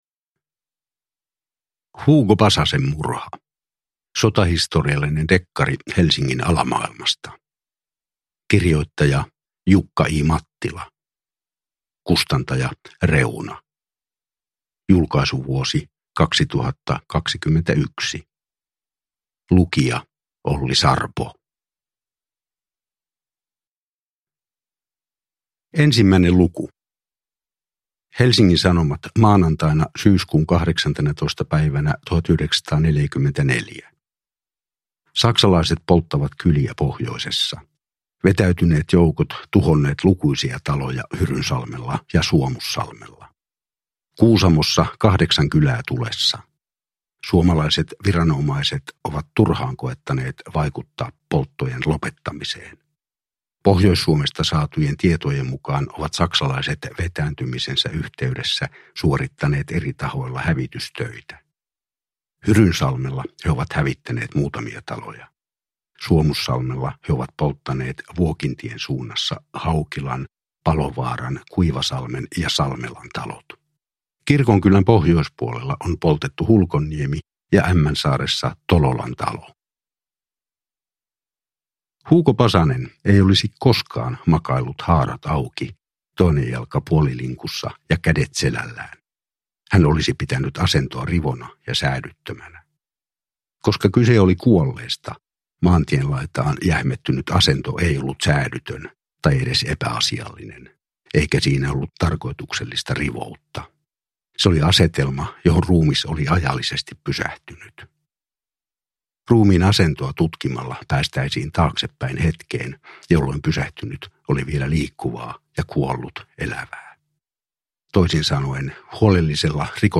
Hugo Pasasen murha – Ljudbok – Laddas ner